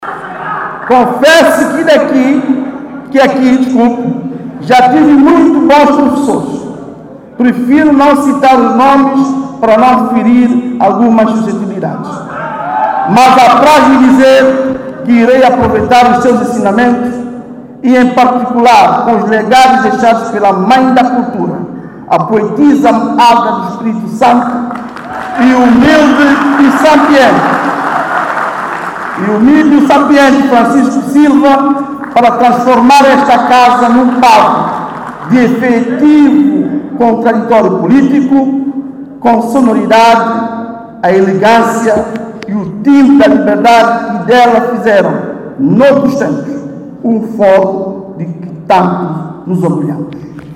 São-Tomé, 23 Nov. ( STP-Press ) – O novo Presidente da Assembleia Nacional, o Parlamento de São Tomé e Príncipe, Delfim Neves disse quinta-feira no seu discurso de investidura que irá apoiar-se nos ensinamentos de Alda Espírito Santo e de Francisco Silva para “transformar ” a casa parlamentar num  “palco de efectivo contraditório político”.
Ouça o Presidente do Parlamento Delfim Neves